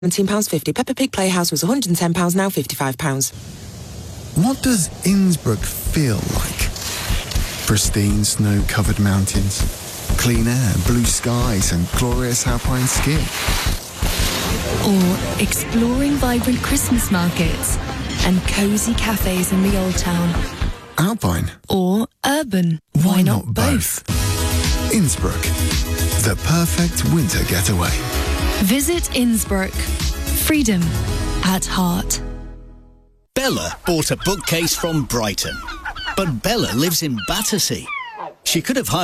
Radiospot Sommer deutsch
Heart_London_ab-Sek.-5.mp3